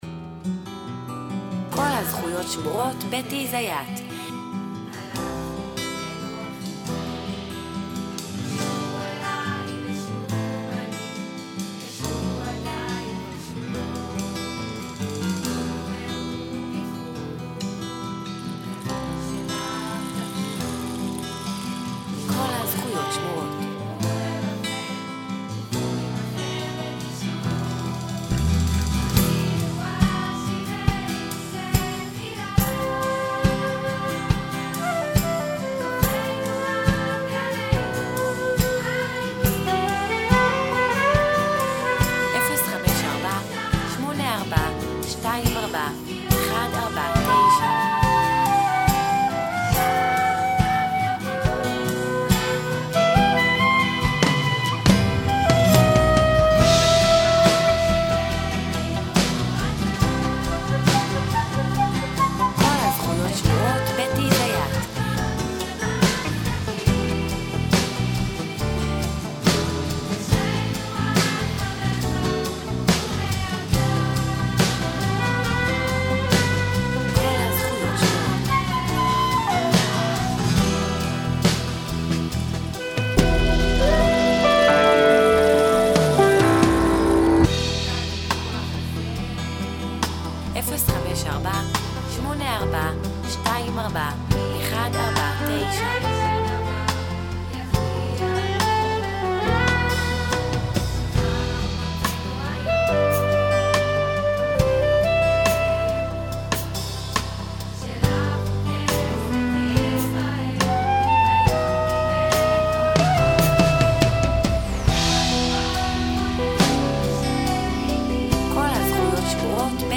ושונה מפעם לפעם גם מבחינה הרמונית וגם מבחינה עיבודית.
את השירה ממש הנמכתי על מנת שתוכלנה להקשיב לכלים